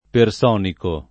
[ per S0 niko ]